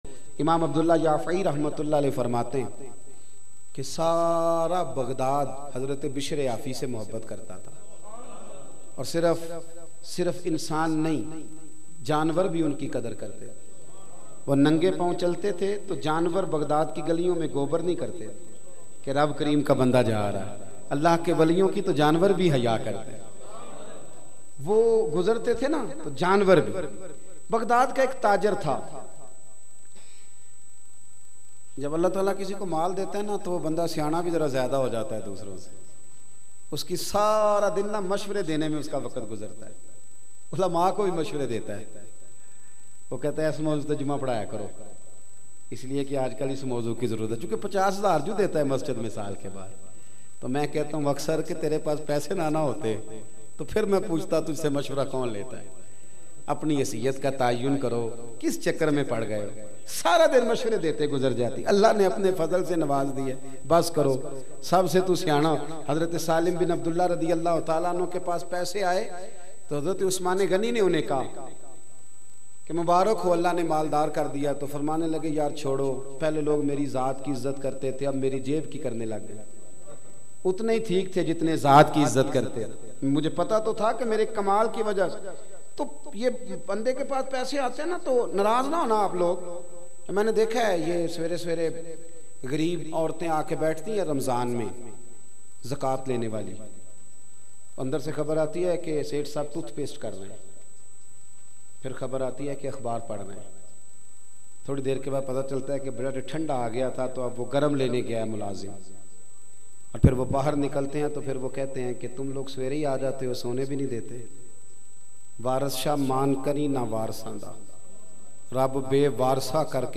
Story of Hazrat Biishr-Al-Hafi Hambli Bayan MP3 Download in best audio quality.